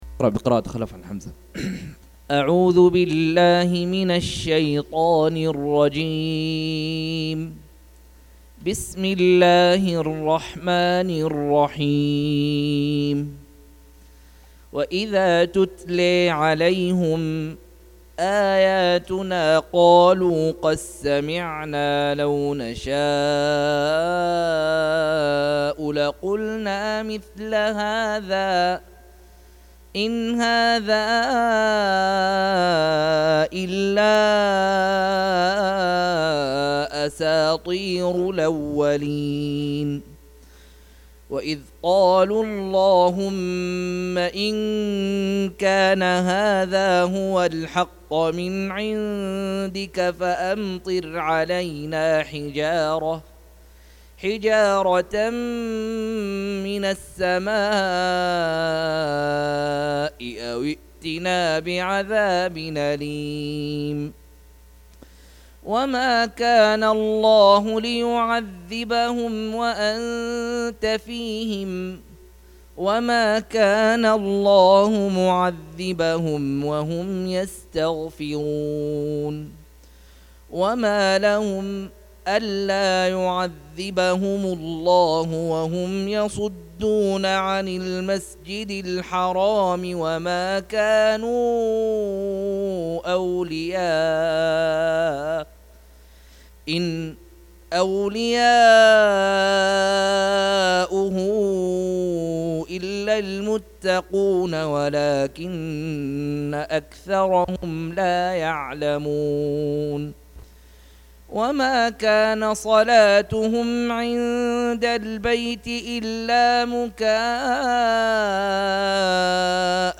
169- عمدة التفسير عن الحافظ ابن كثير رحمه الله للعلامة أحمد شاكر رحمه الله – قراءة وتعليق –